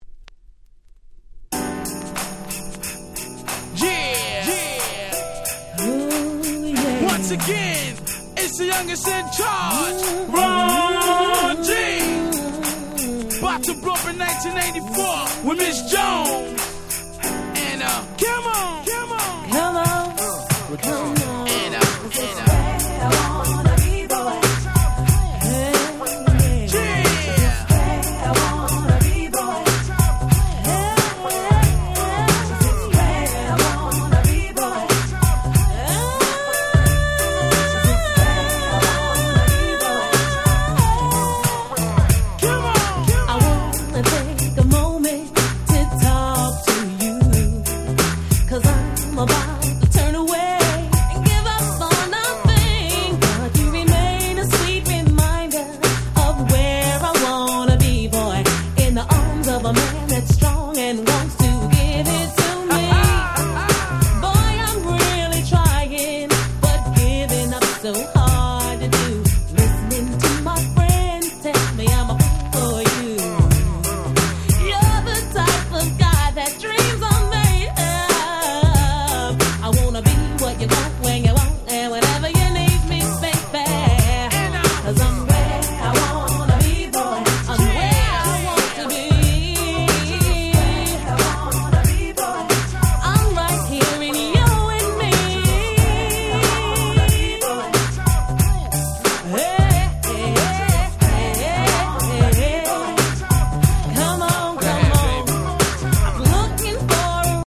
94' R&B Classics !!
ヒップホップソウル